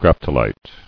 [grap·to·lite]